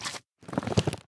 Minecraft Version Minecraft Version 25w18a Latest Release | Latest Snapshot 25w18a / assets / minecraft / sounds / mob / magmacube / jump4.ogg Compare With Compare With Latest Release | Latest Snapshot